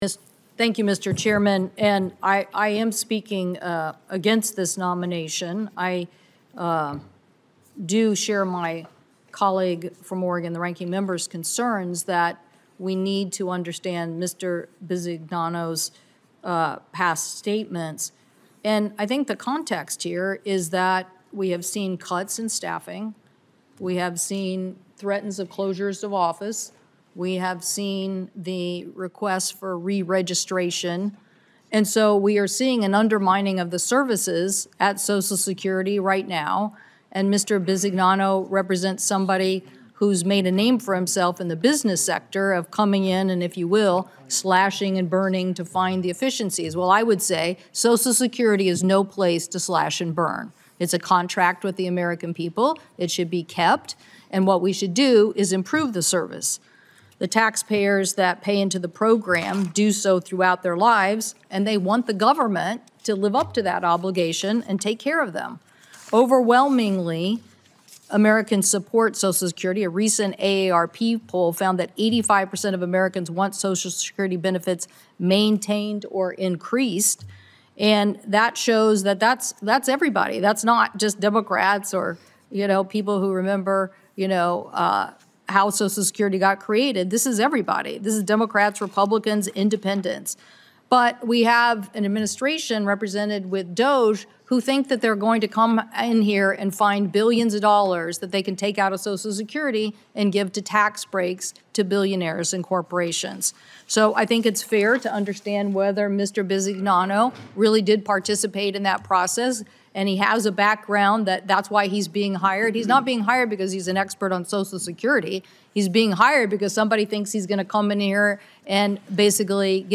WASHINGTON, D.C. – Today, U.S. Senator Maria Cantwell (D-WA), senior member of the Senate Finance Committee and ranking member of the Senate Committee on Commerce, Science, and Transportation, spoke against advancing Frank Bisignano – President Donald Trump’s pick to serve as Commissioner of the Social Security Administration – at a Senate Finance Committee Executive Session.